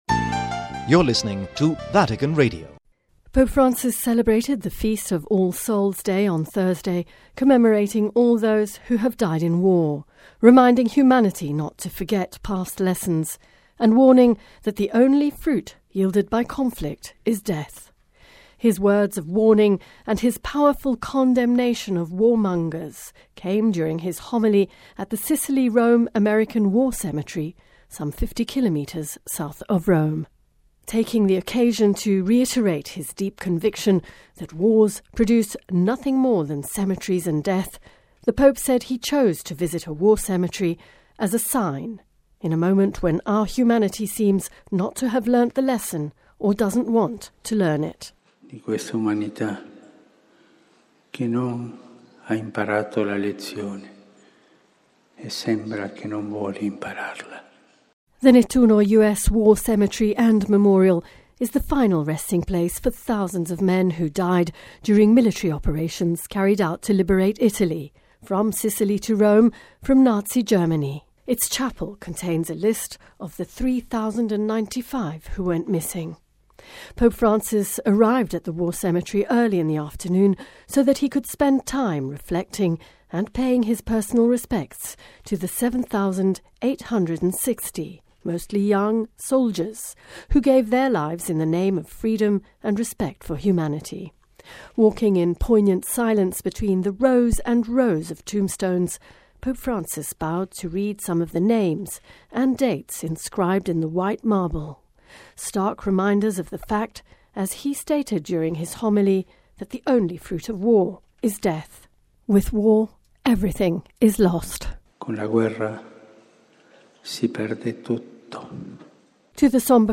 His words of warning and his powerful condemnation of warmongers came during his homily at the Sicily-Rome American War Cemetery some 50 kilometers south of Rome.